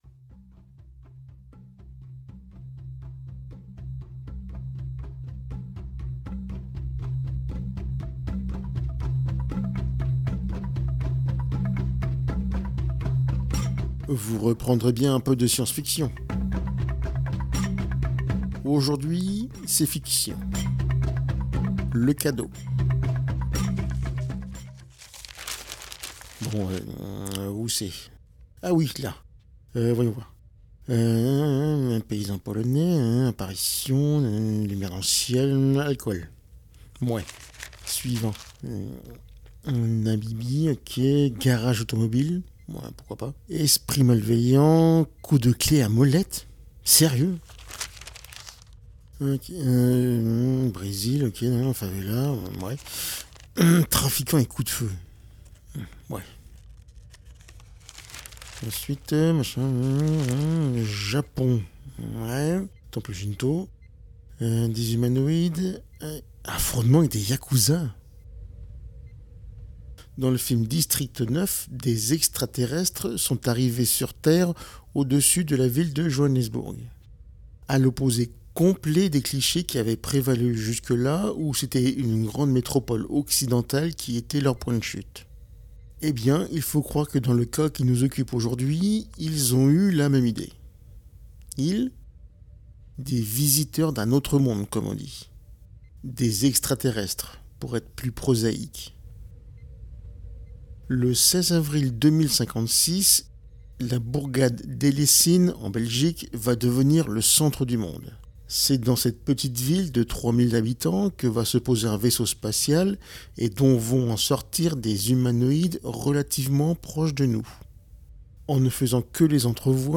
Aujourd’hui c’est fiction Le cadeau Bruit de journal Bon où c’est ?